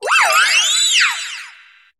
Cri de Créfollet dans Pokémon HOME.